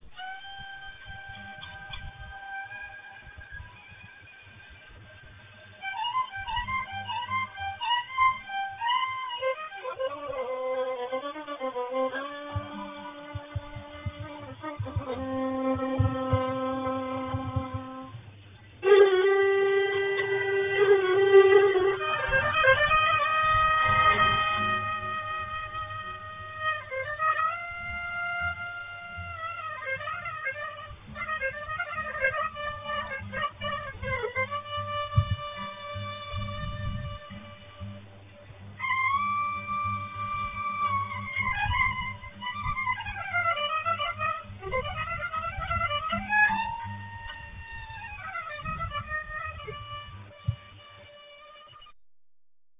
Romanès, cirque tzigane à Brest
violon
contrebasse
accordéon
Extraits du spectacle (en RealAudio)